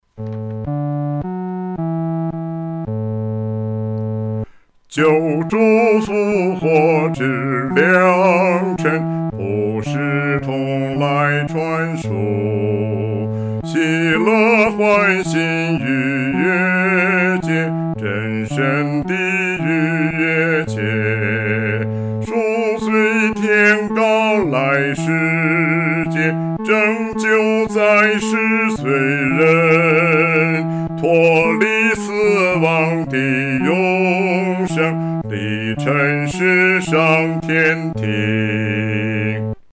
独唱（第四声）